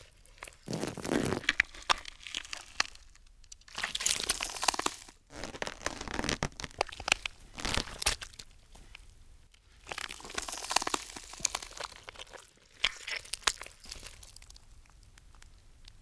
rocketlauncher_idle.wav